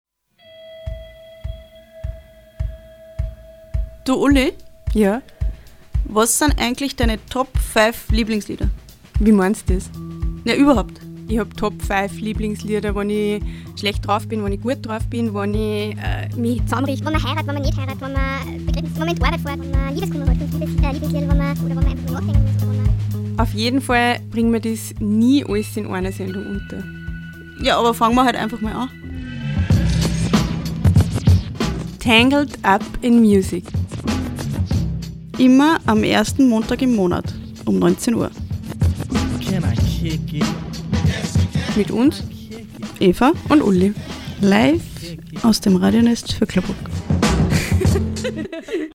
Sendungstrailer
TRAILER-TANGLED-UP-IN-MUSIC-JEDEN-1-MONTAG-UM-19-UHR_LANG.mp3